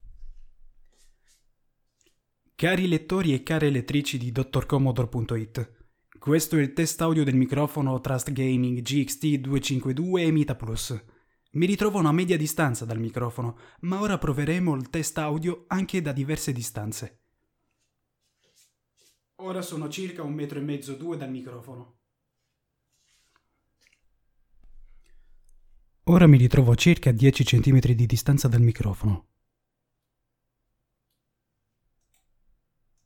Il suono è corposo e molto pulito.
Le prove che sentirete saranno divise in acquisizione audio originale e, successivamente, con qualche piccolo ritocco in post produzione (rimozione rumore).
Le registrazioni sono state svolte con il microfono installato sul braccio regolabile, filtro antivento e pop filter. Non sono stati usati pannelli fonoassorbenti.
Voce con post produzione
Test-audio-riduzione-rumori.mp3